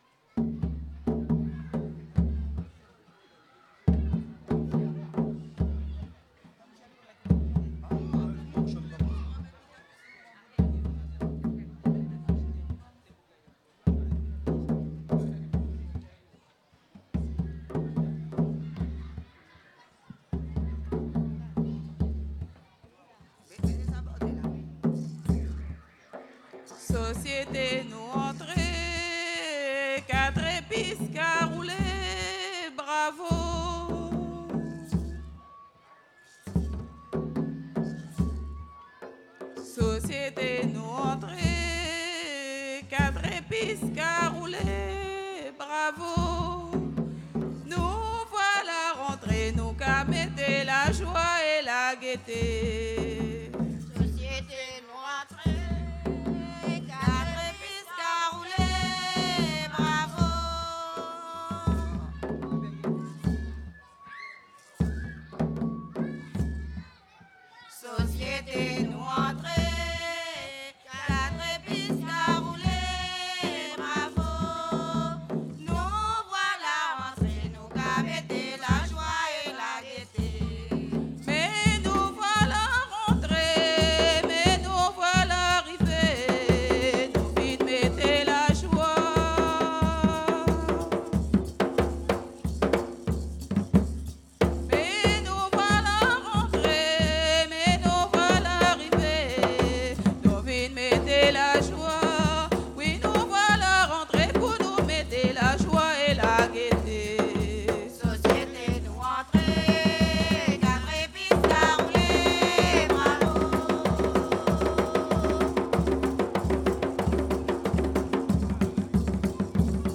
danse : grajévals (créole)
Pièce musicale inédite